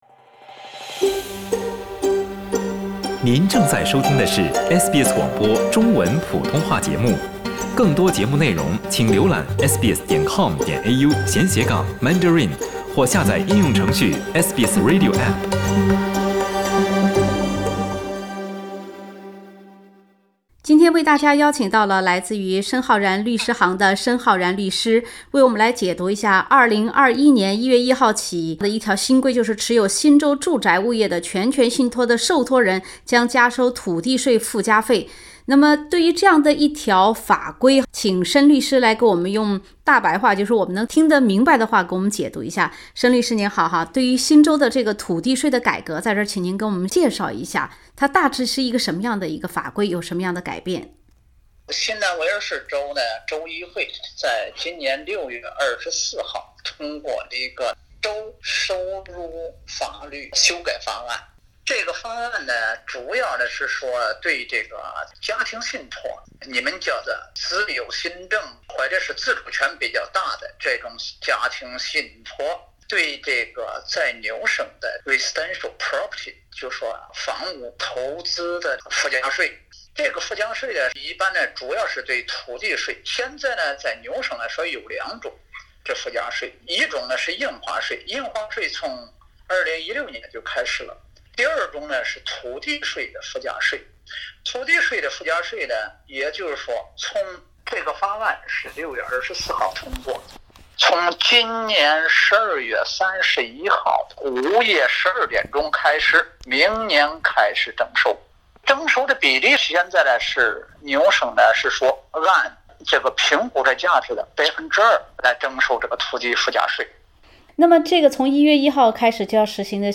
2021年1月1日起，新州住宅物业的全权信托（包括遗嘱信托）的土地税附加费有大调整。（点击封面图片，收听完整采访）